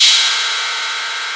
TR-808 sounds
Cymbal
808cym.wav